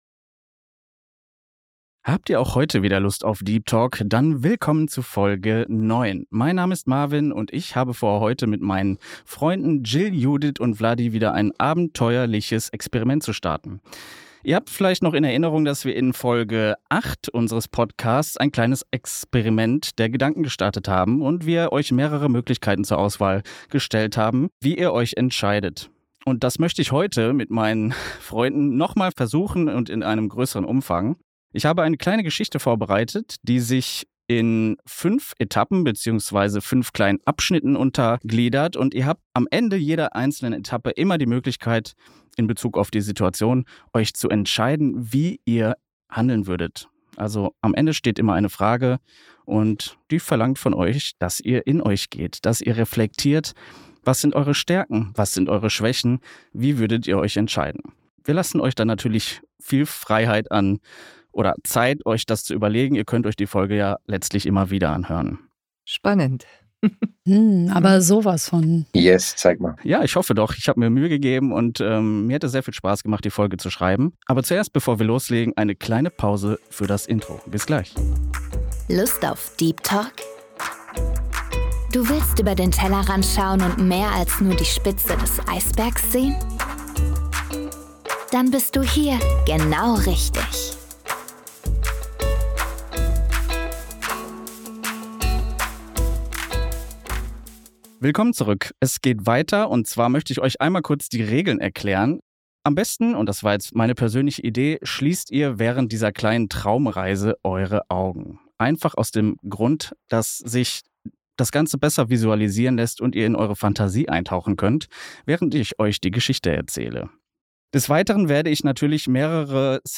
Heute laden dich unsere vier Podcast-Freunde zu einem Gedankenexperiment ein.